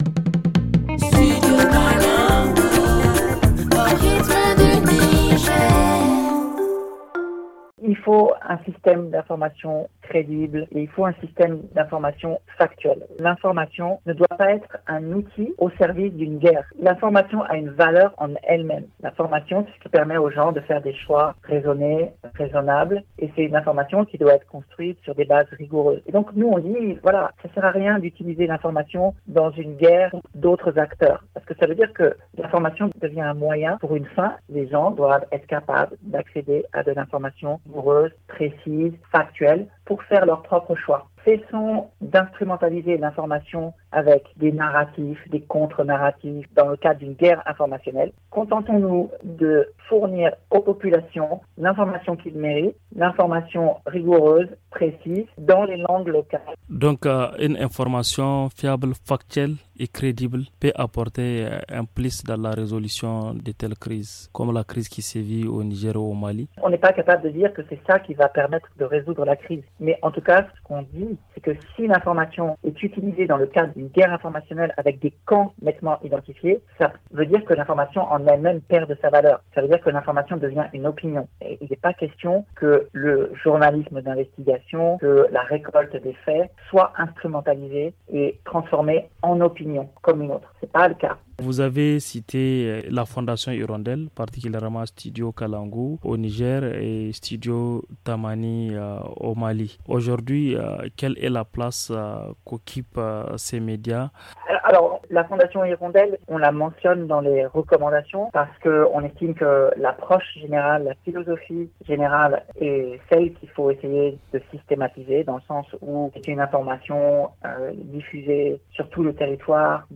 Le magazine en français